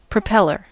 Ääntäminen
IPA : /pɹəˈpɛl.ə(ɹ)/